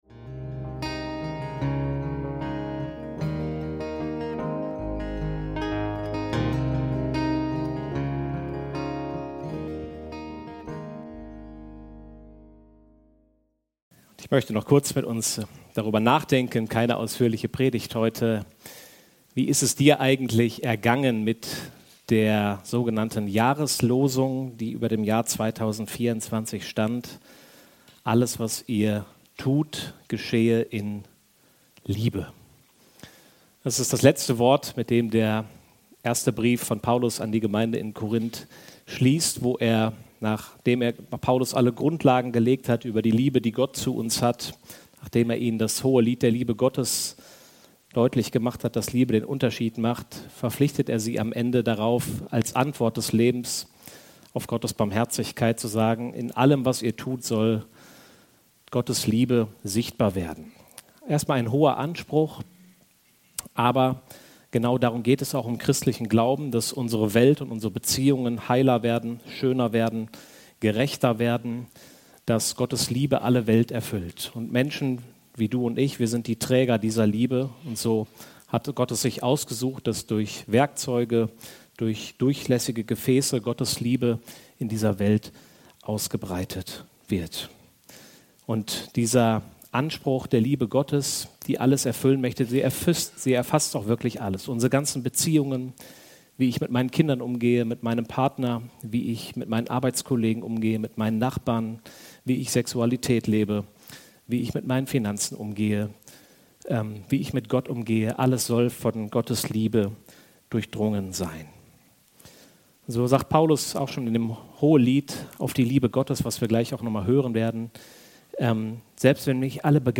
Jahresschlussandacht 2024